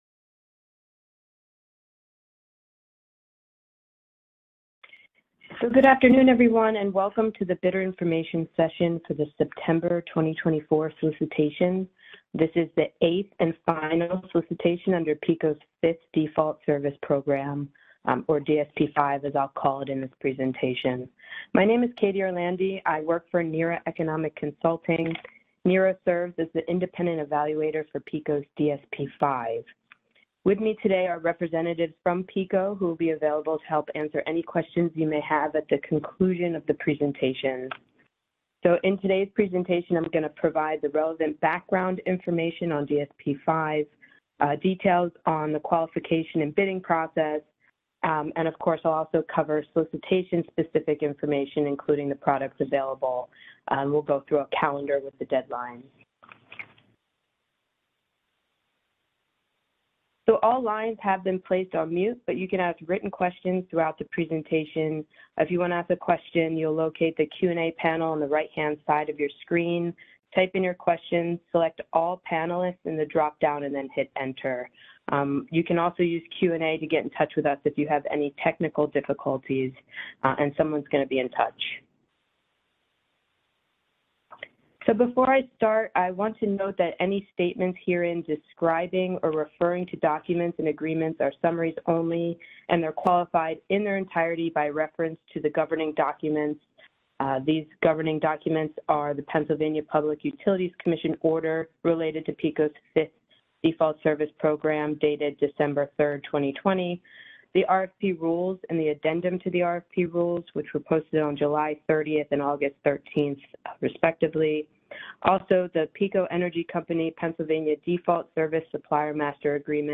Bidder Information Webcast Audio